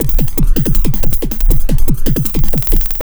52JUNGL160.wav